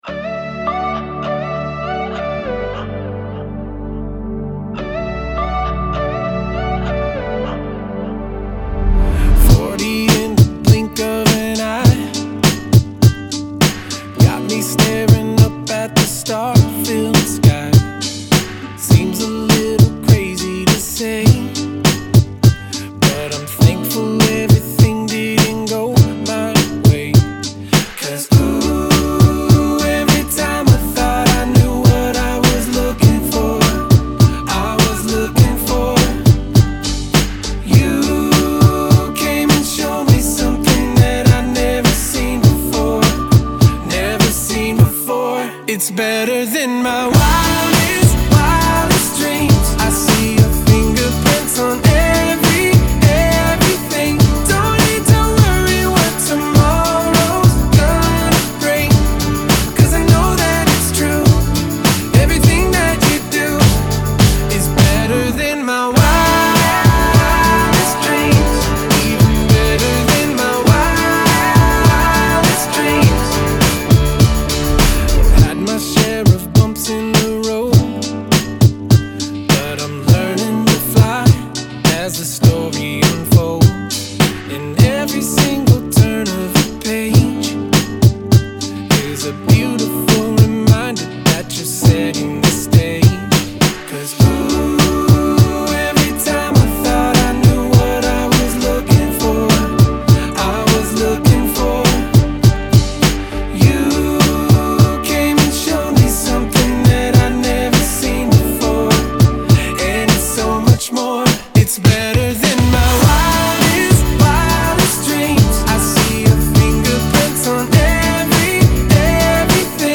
362 просмотра 324 прослушивания 59 скачиваний BPM: 102